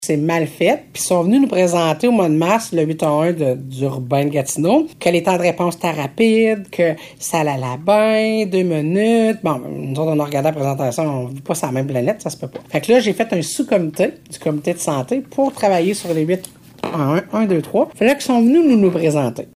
La population ne connait pas les 3 options offertes au 811. La préfète Vallée-de-la-Gatineau, Chantal Lamarche, explique :